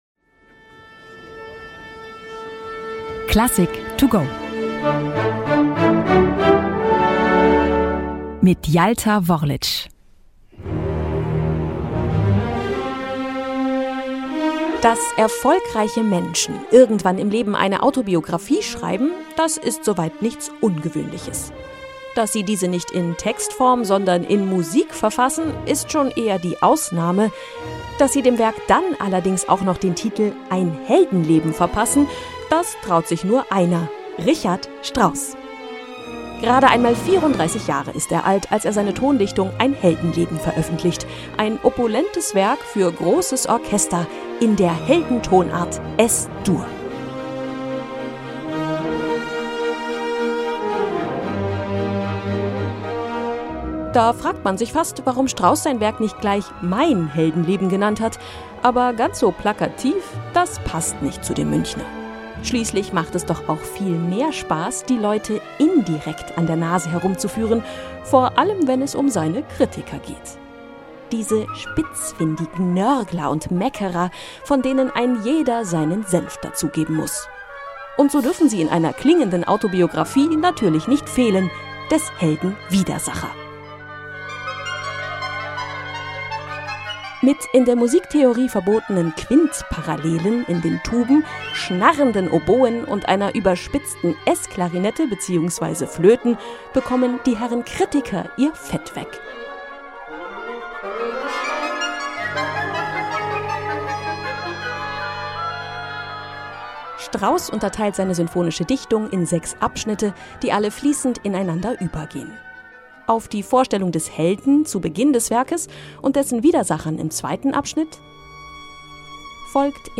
vorgestellt in der Konzerteinführung für unterwegs